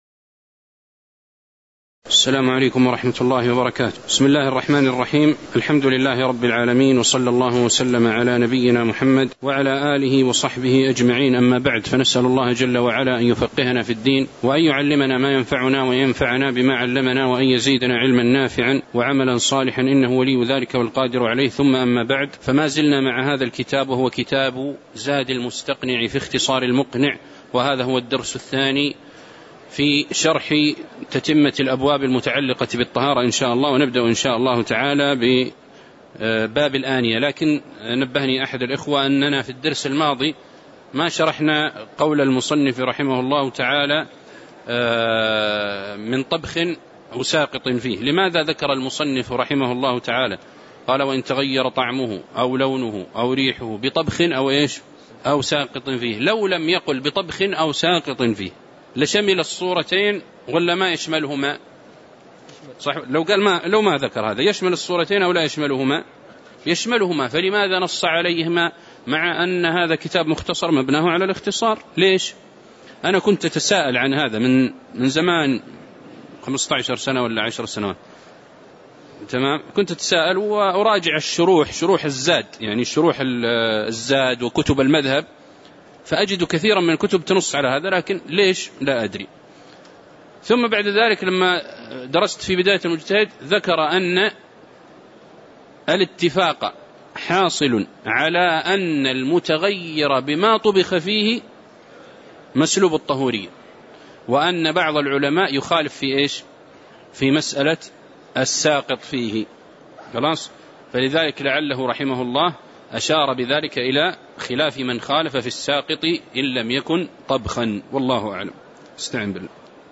تاريخ النشر ٨ محرم ١٤٤٠ هـ المكان: المسجد النبوي الشيخ